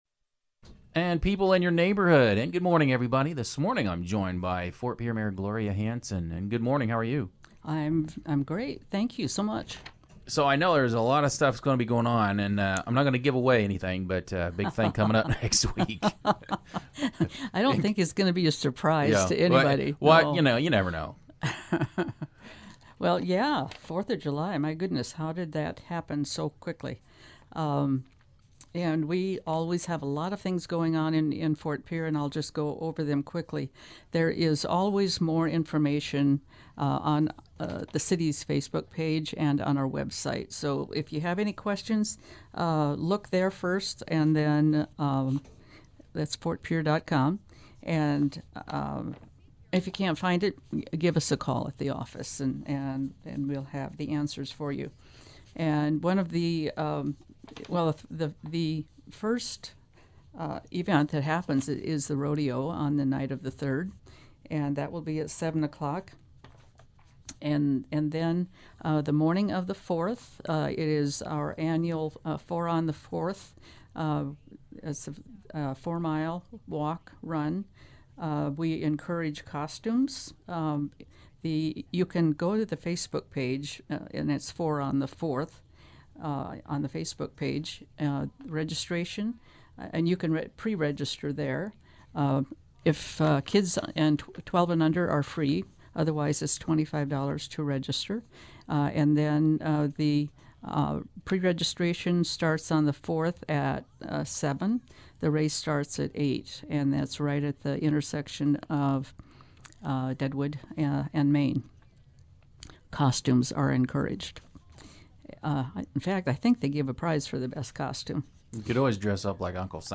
speaks with Mayor Hanson about the upcoming 4th of July festivities.